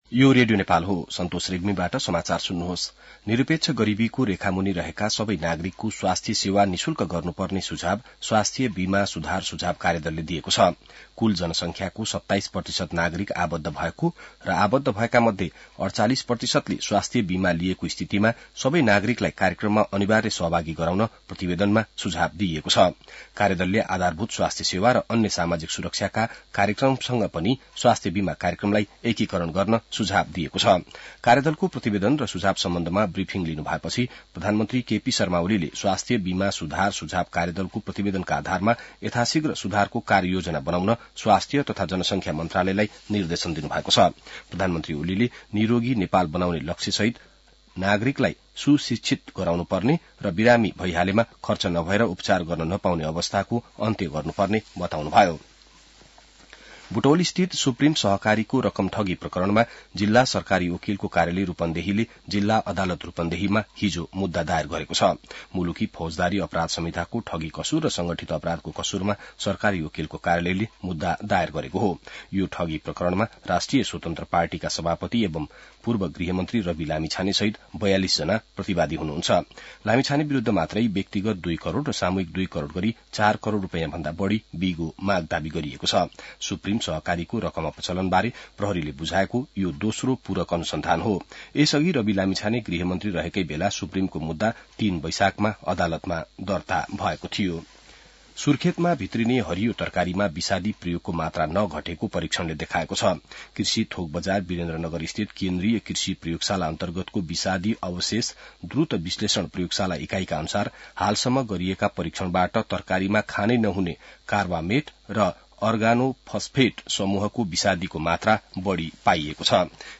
बिहान ६ बजेको नेपाली समाचार : ८ माघ , २०८१